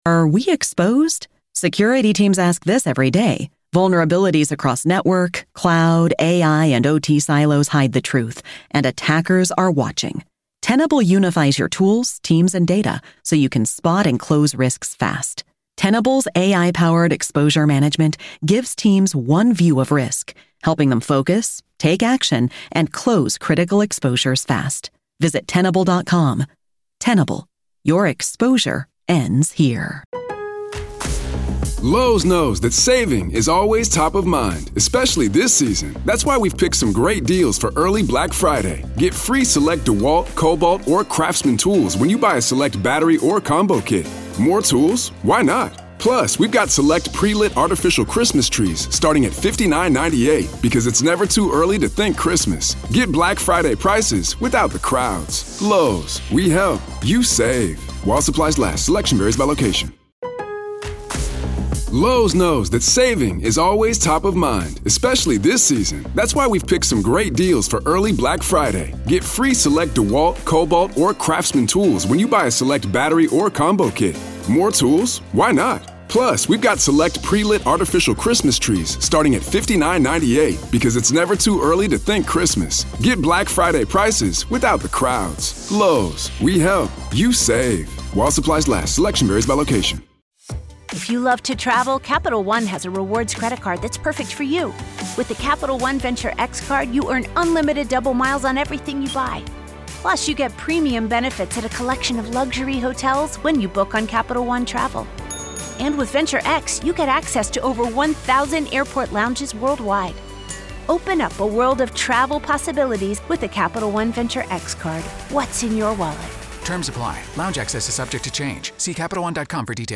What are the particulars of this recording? You’ll hear unfiltered courtroom audio, direct from the trial